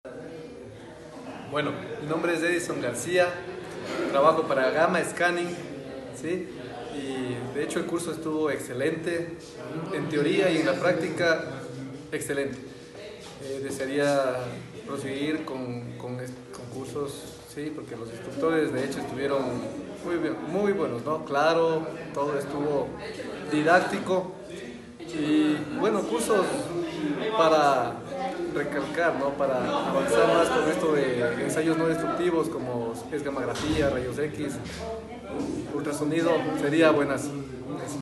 Testimonio: